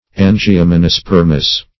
Search Result for " angiomonospermous" : The Collaborative International Dictionary of English v.0.48: Angiomonospermous \An`gi*o*mon`o*sper"mous\ ([a^]n`j[i^]*[-o]*m[o^]n`[-o]*sp[~e]r"m[u^]s), a. [Angio- + monospermous.]
angiomonospermous.mp3